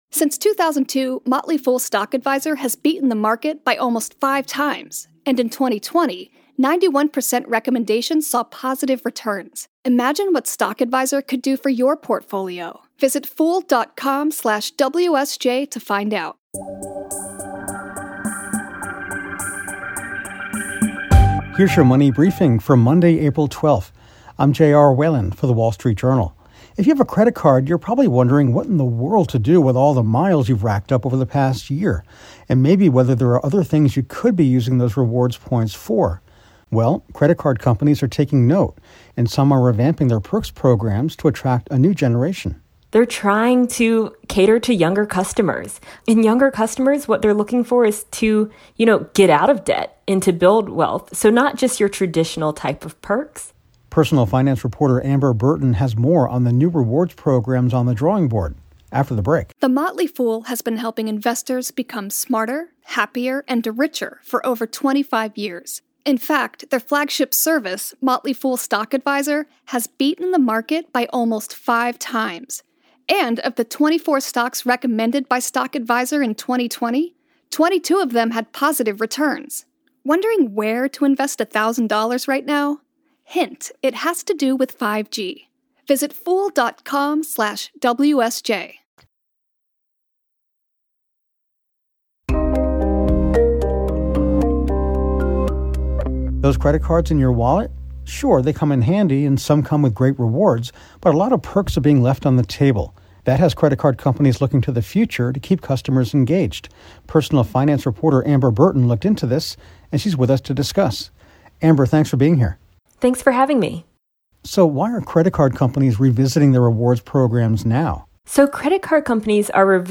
Credit card companies are adding a new slate of perks, including transferring those reward points in cryptocurrency, rent payments or even green initiatives. Personal finance reporter